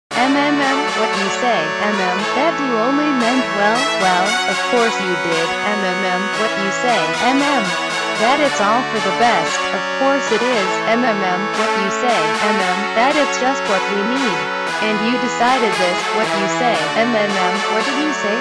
Piano whatevers